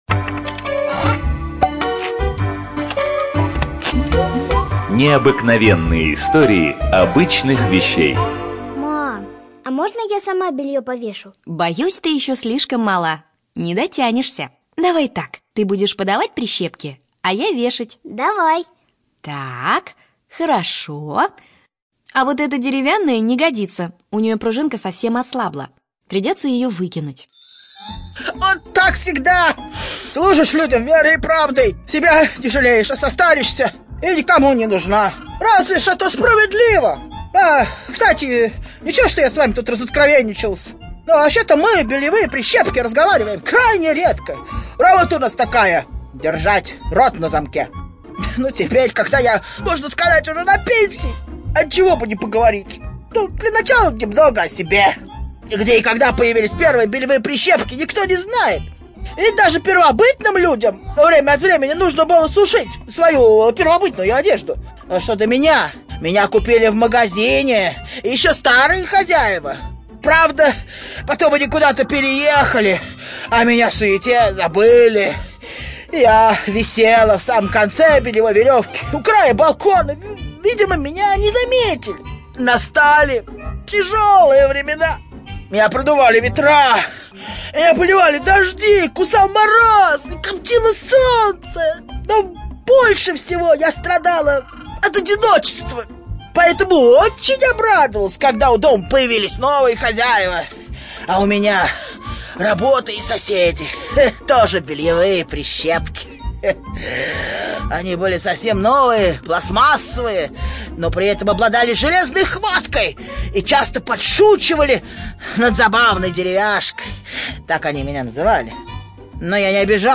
ЧитаетДетское радио
Студия звукозаписиДетское радио